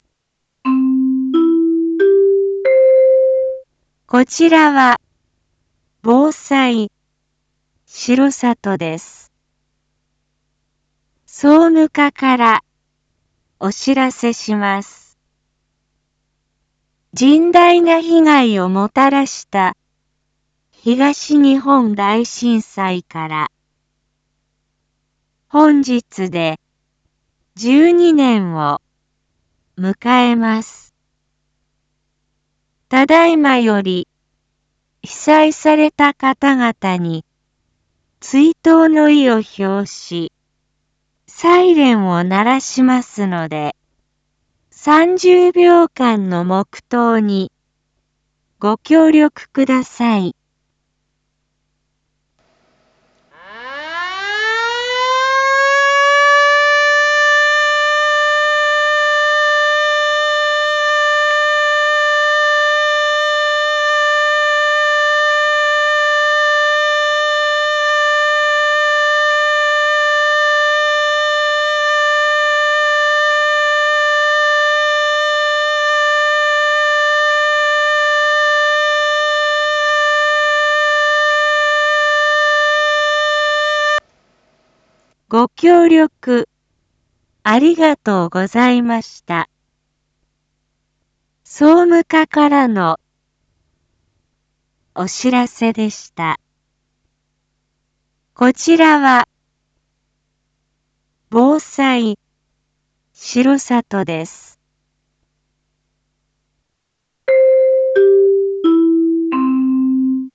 Back Home 一般放送情報 音声放送 再生 一般放送情報 登録日時：2023-03-11 14:47:02 タイトル：東日本大震災追悼放送 R5.3.11 インフォメーション：こちらは、防災しろさとです。
サイレン吹鳴 30秒程度 ・・・黙祷・・・ ご協力ありがとうございました。